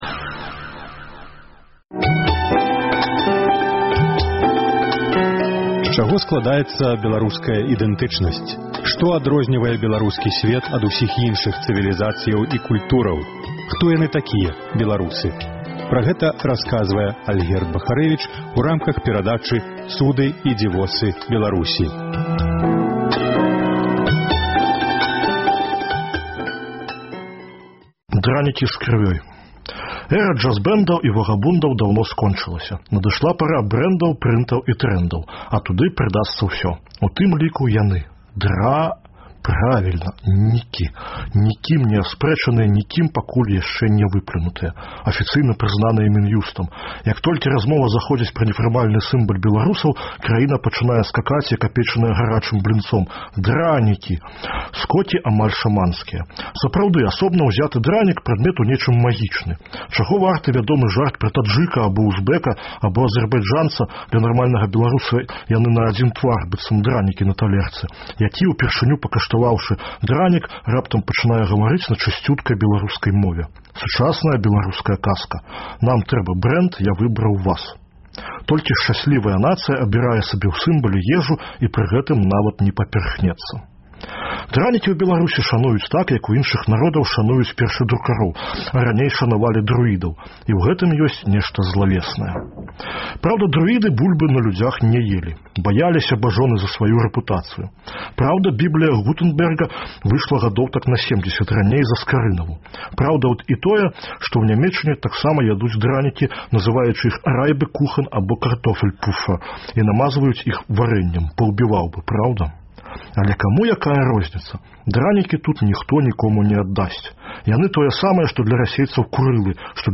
Пра гэта расказвае Альгерд Бахарэвіч у рамках новай радыёперадачы і цыклю эсэ «Цуды і дзівосы Беларусі».